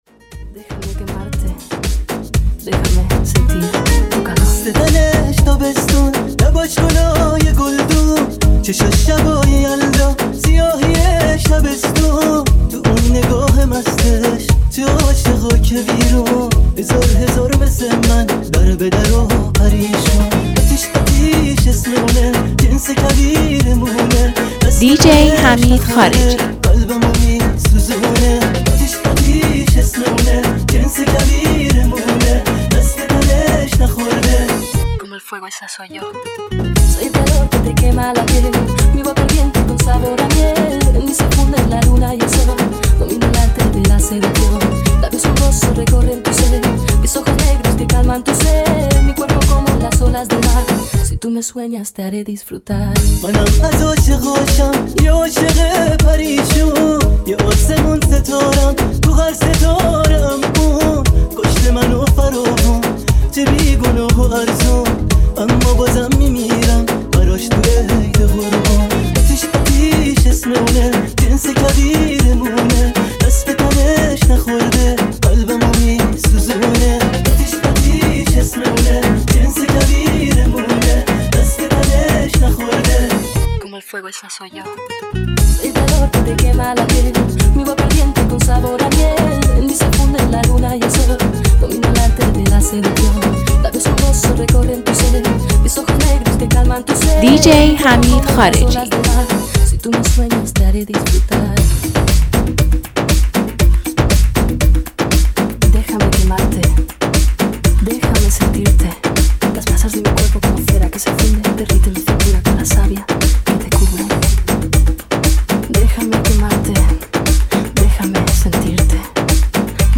این ریمیکس زیر خاکی و پرطرفدار با تنظیم خاص